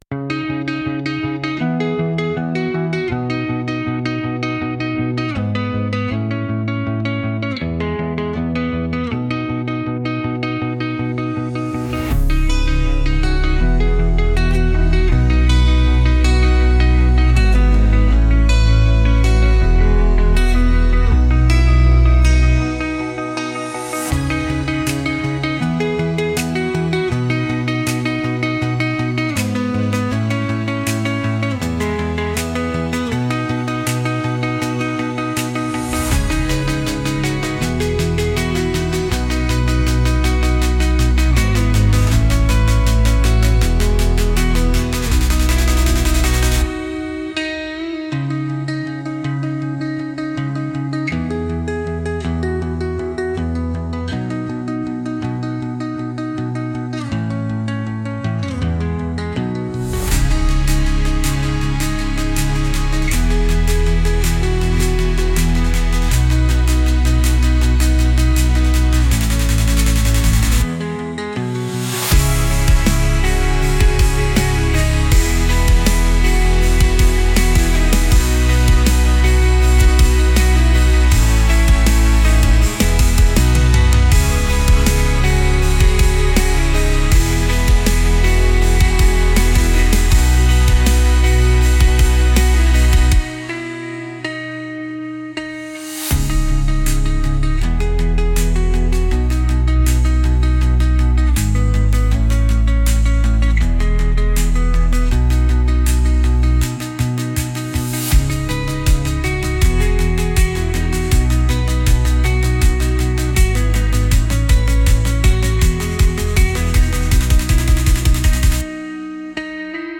Genre: Melancholic Mood: Acoustic Editor's Choice